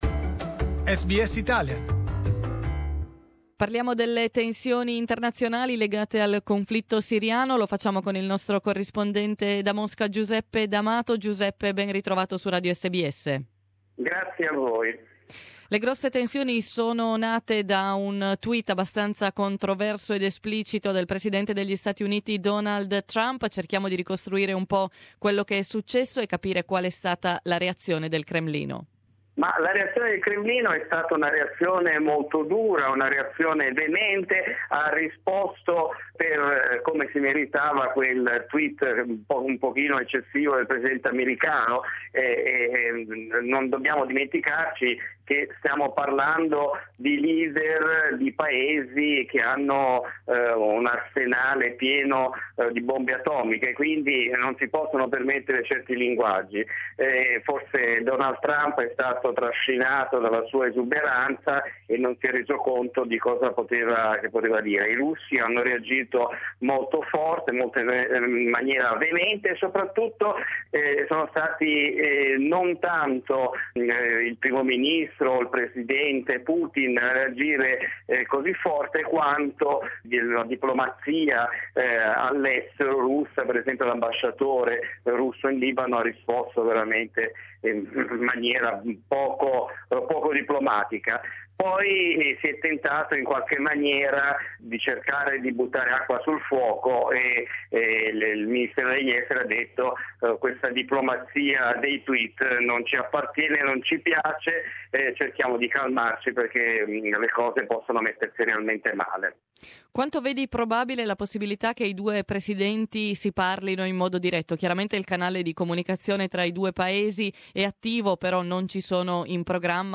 reports from Moscow